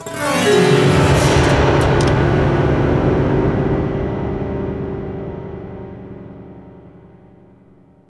SI2 PIANO0BL.wav